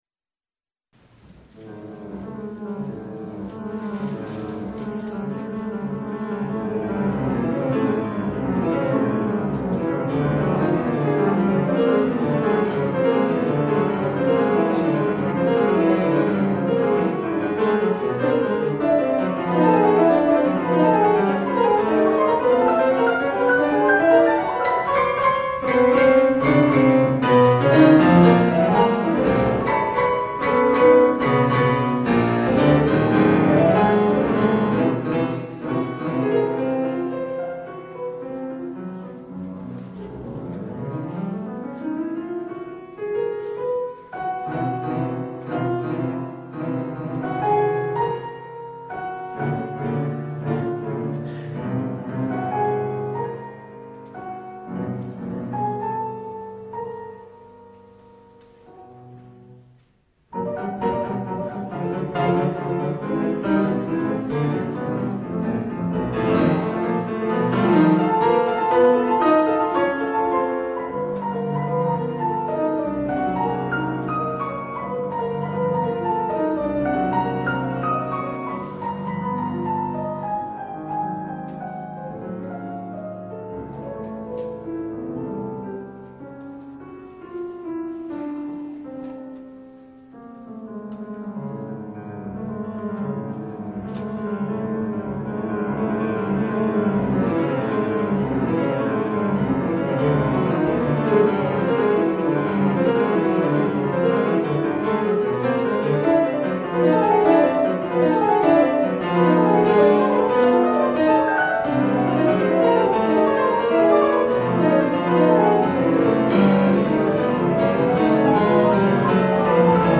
Keyboard
Instrumental